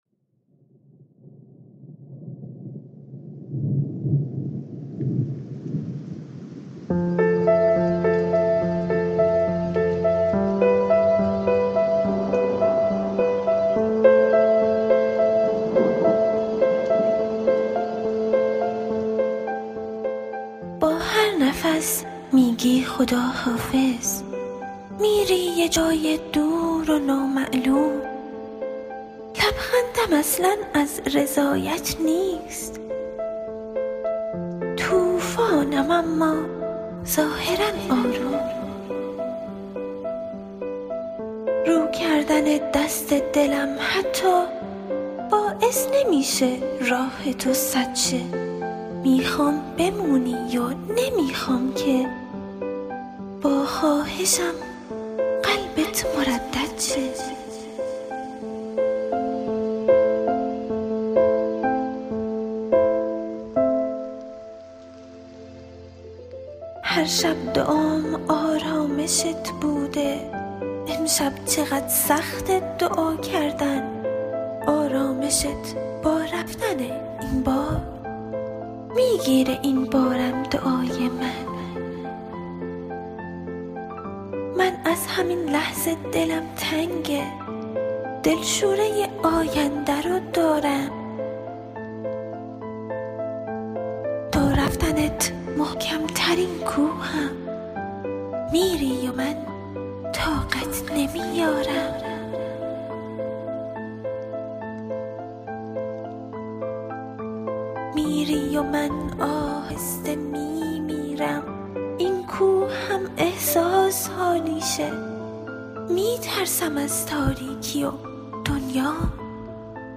دکلمه
میکس و مسترینگ